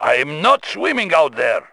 Here are some additional Boris voicelines